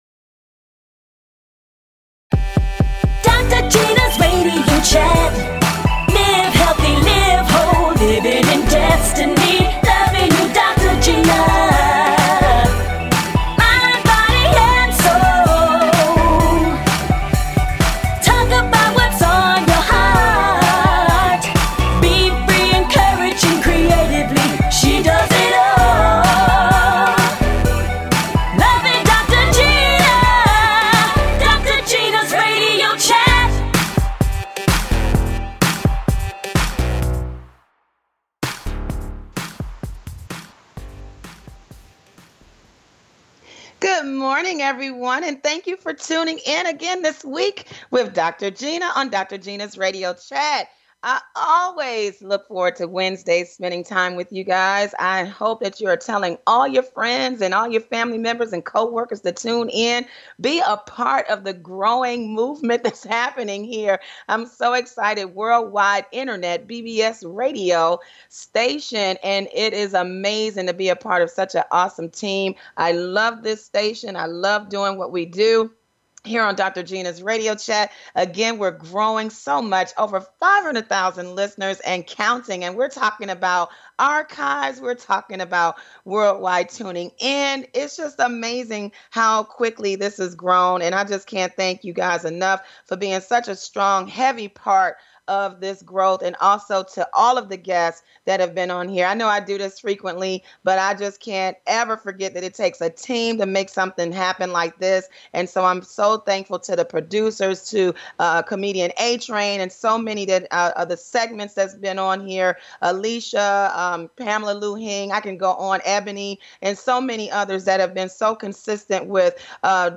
Fun! Exciting! And full of laughter!
A talk show of encouragement.